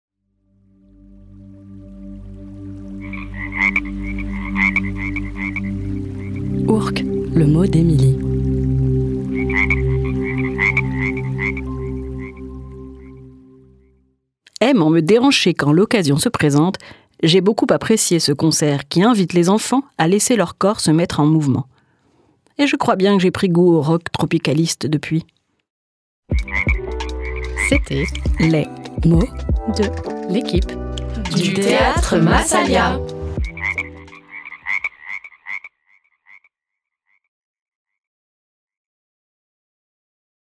Une vraie batucada bruitiste !
ROCK TROPICALISTE